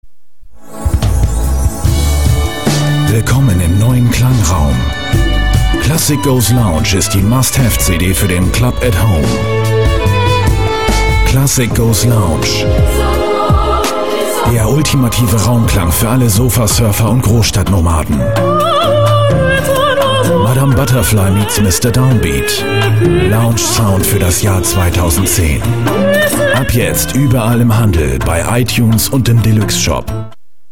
Vielseitig einsetzbare sonore warme Stimme für Synchron, Dokumentation, Werbung, Industriefilm, E-Learning usw.
Kein Dialekt
Sprechprobe: Sonstiges (Muttersprache):
german voice over artist